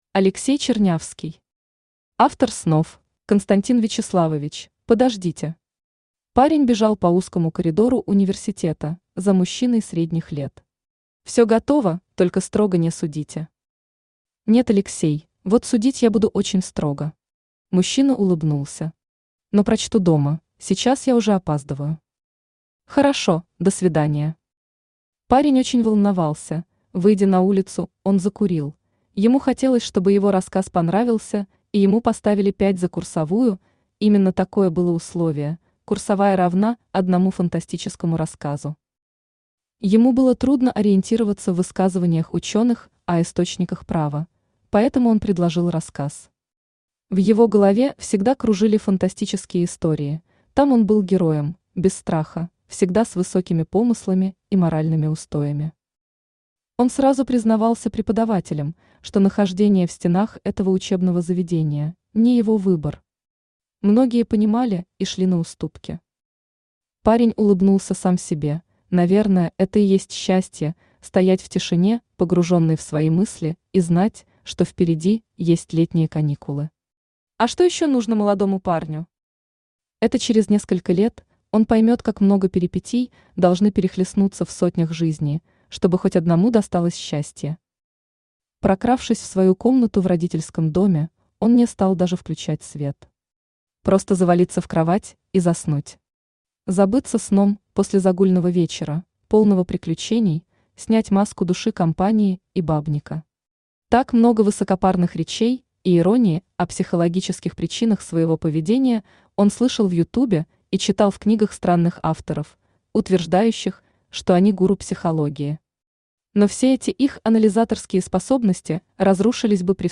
Аудиокнига Автор Снов | Библиотека аудиокниг
Aудиокнига Автор Снов Автор Алексей Андреевич Чернявский Читает аудиокнигу Авточтец ЛитРес.